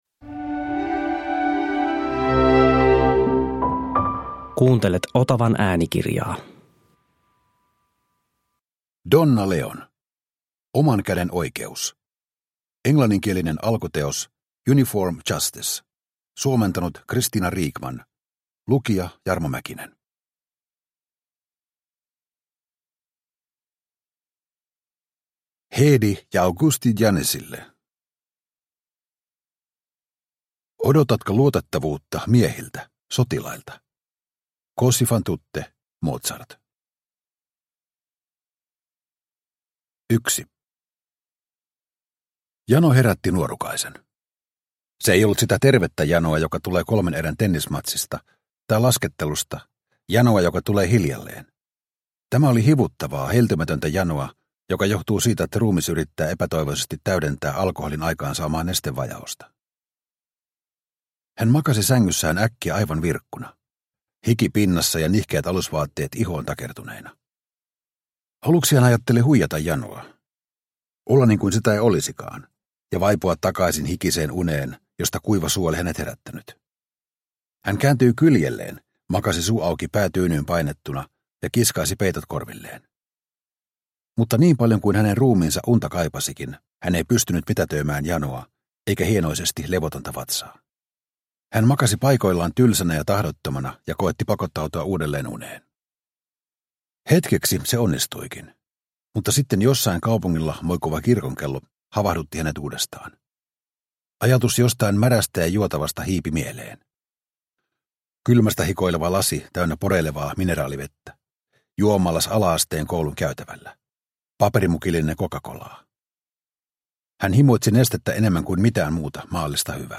Oman käden oikeus – Ljudbok – Laddas ner